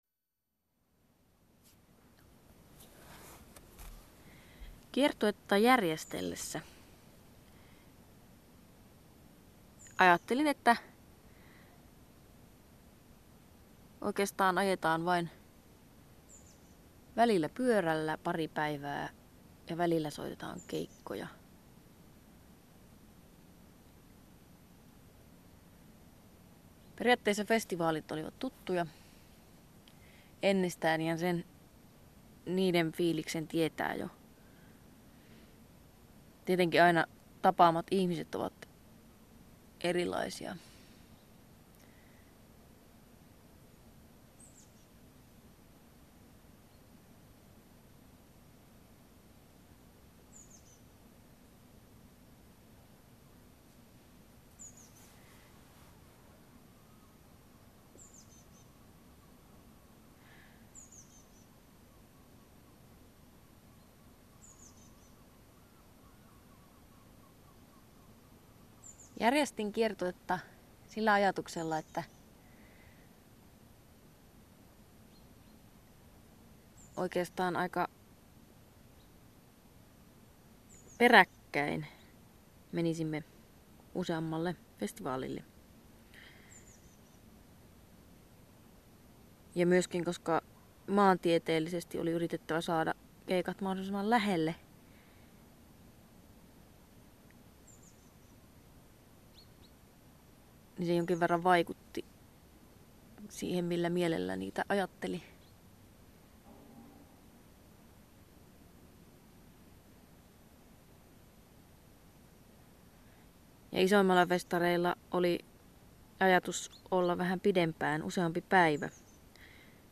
Tulos on rauhallinen, syvä sukellus kiertueen herättämiin ajatuksiin ja tunteisiin.